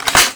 assets/pc/nzp/sounds/weapons/thomp/boltback.wav at 180cc041c83f7f6b565d5d8fbf93c3f01e73f6a5
boltback.wav